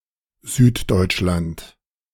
Southern Germany (German: Süddeutschland, [ˈzyːtˌdɔʏtʃlant]